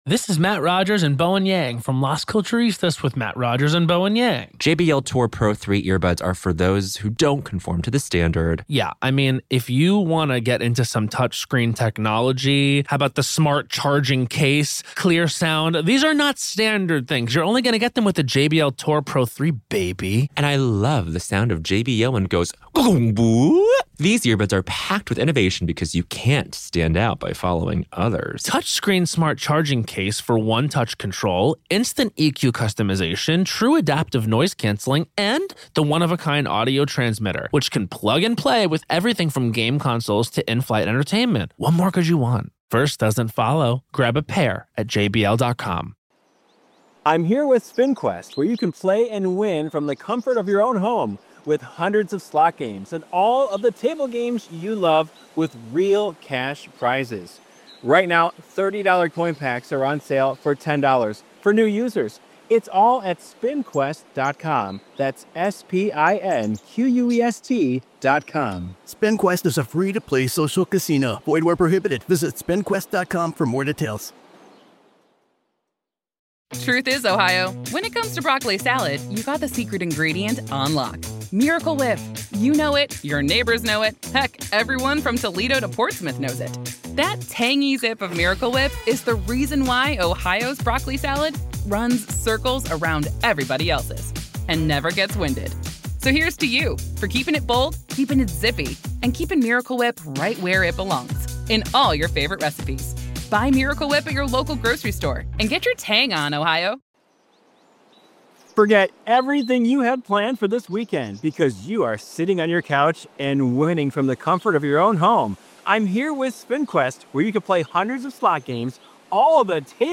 Each recording features the full day’s testimony, witness questioning, objections, rulings, and all live developments direct from the courtroom — presented exactly as they happened, without edits or commentary.